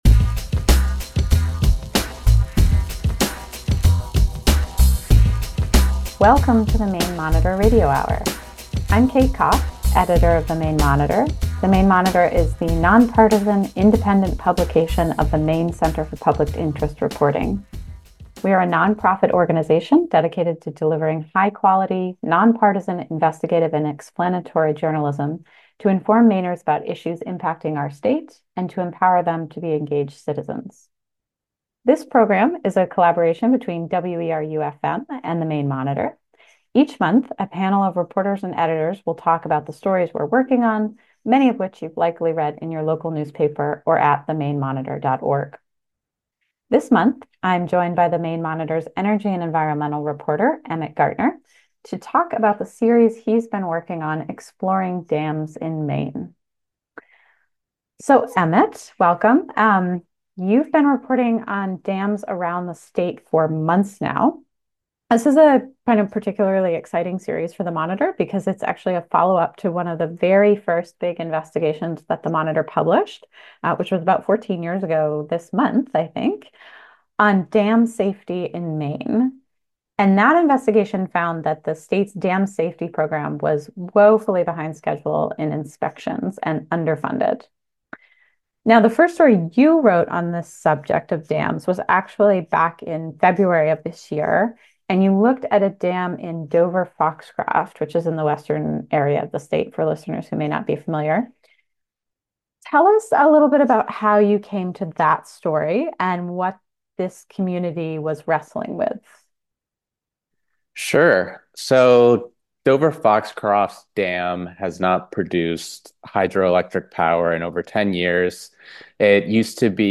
The Maine Monitor Radio Hour is a public affairs program that airs monthly on WERU Community Radio, in collaboration with The Maine Monitor (a publication of the nonprofit Maine Center for Public Interest Reporting).